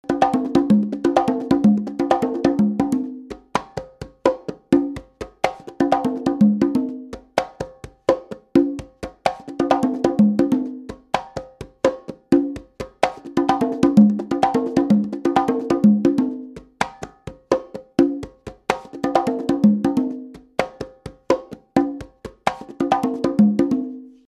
LP Patato Fiberglass Quinto, Conga and Tumbadora | Steve Weiss Music
• 30" tall silhouette, projects deep bass tones and great volume without sacrificing crisp, high sounds
• Hand selected, natural rawhide heads